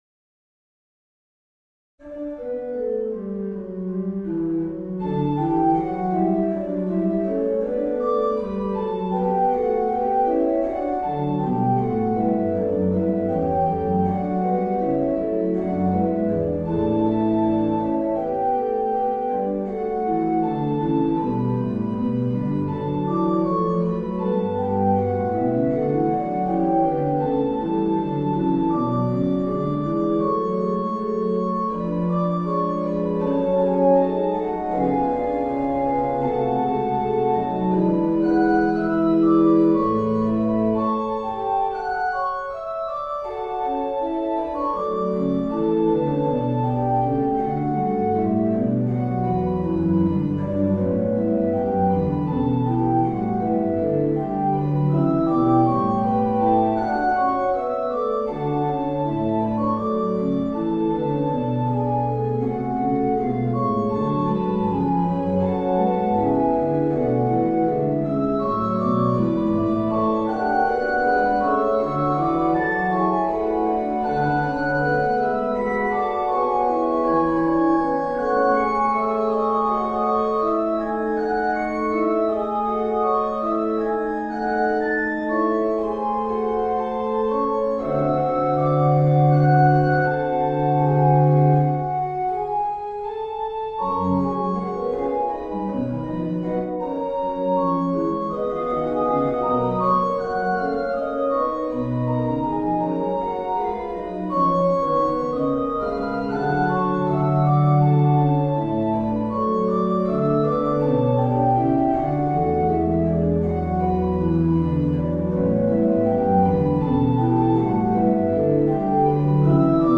Henri Libert, Duo en forme de canon, pour orgue ou harmonium, dédié "A mon ami Charles Quef", alors organiste